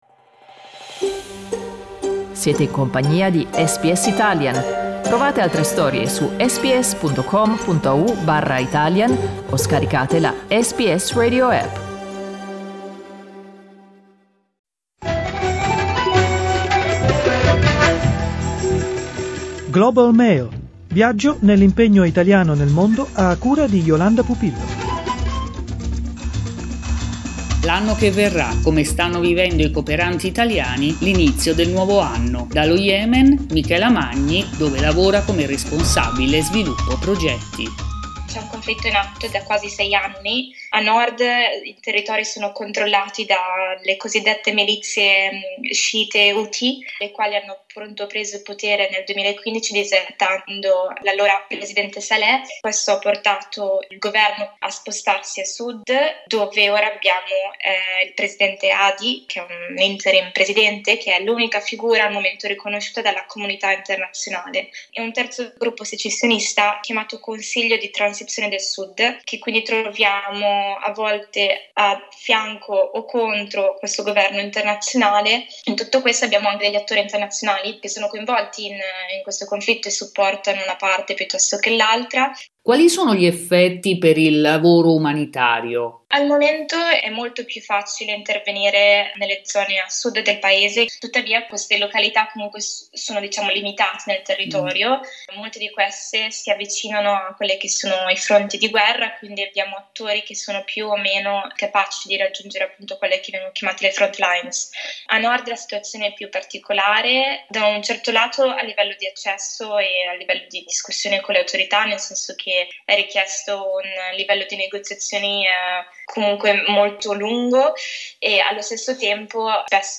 Ascolta l'intervista integrale: